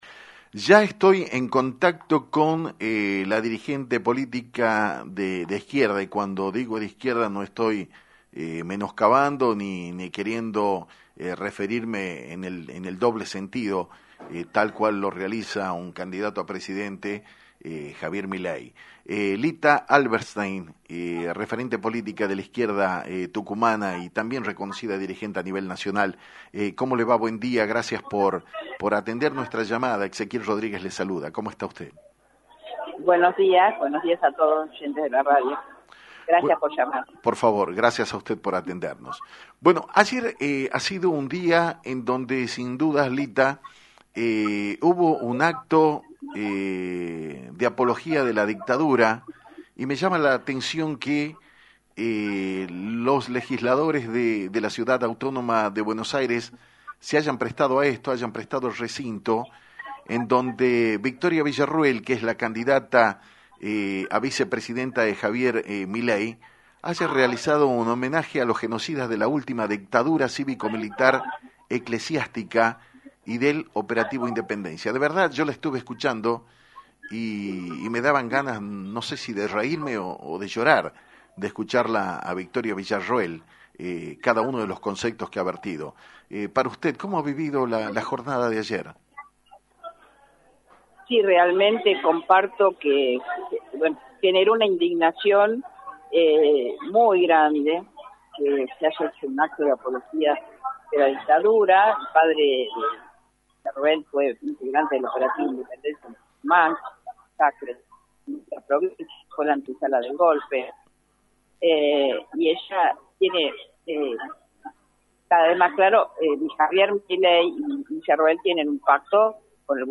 En diálogo con Actualidad en Metro